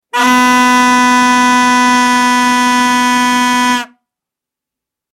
Здесь вы можете слушать и скачивать разные варианты гула: от монотонного гудения до интенсивного рева.
Звук вувузелы лепатата